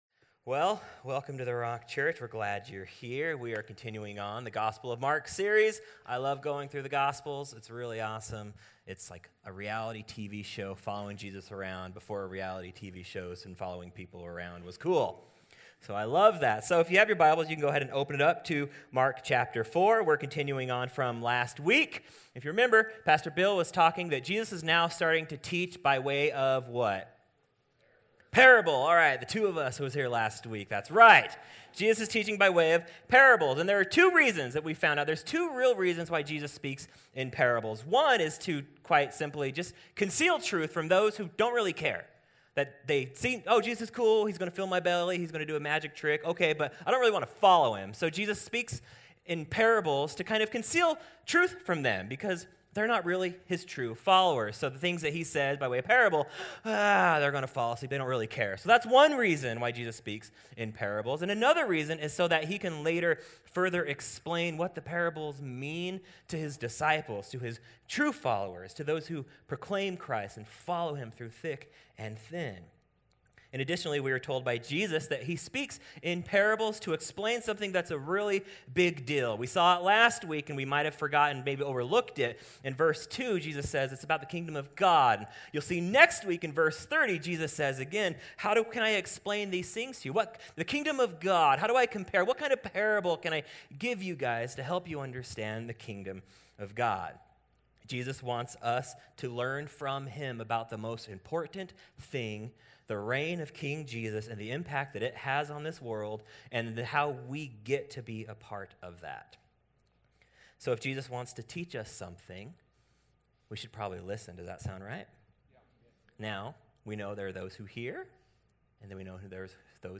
A message from the series "The Gospel of Mark ." In this message, we see Jesus use more imagery to help explain the Kingdom of God. But, what do the parables of shining lamps and seeds being scattered have to do with Christians?